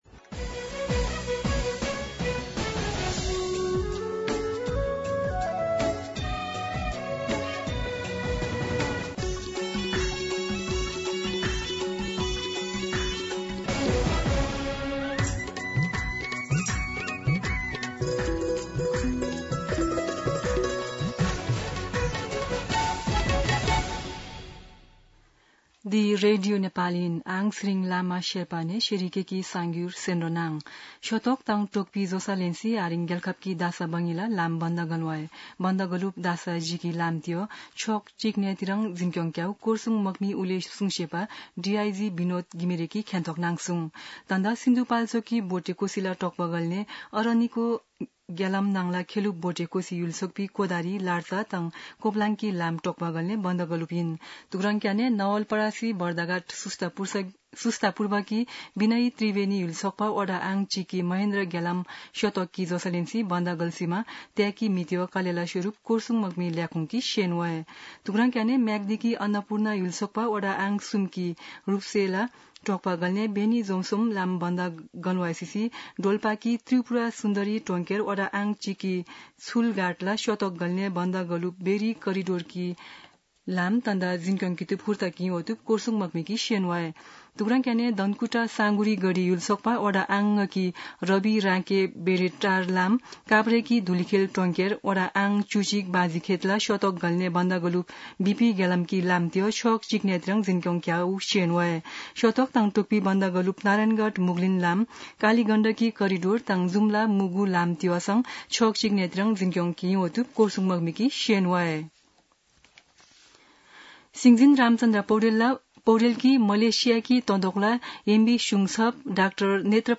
शेर्पा भाषाको समाचार : ५ असार , २०८२
Sherpa-News-2.mp3